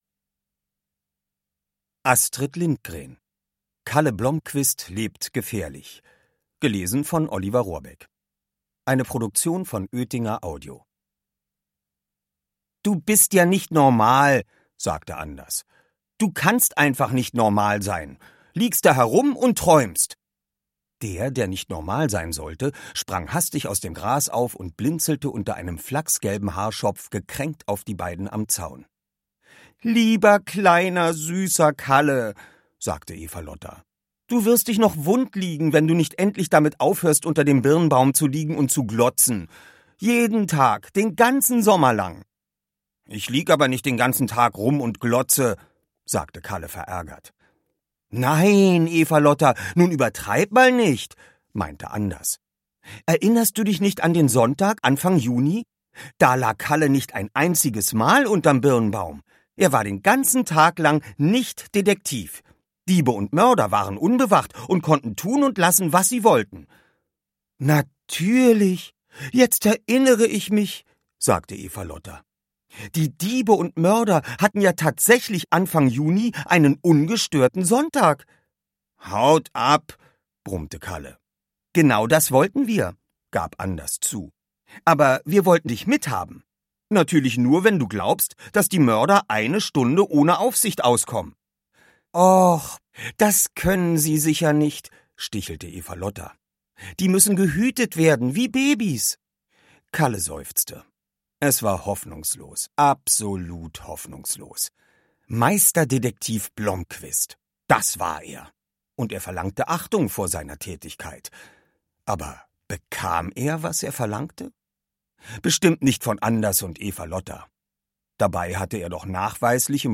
Hörbuch: Kalle Blomquist 2.
Oliver Rohrbeck (Sprecher)